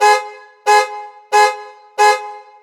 transport
Car Alarm In Parking Structure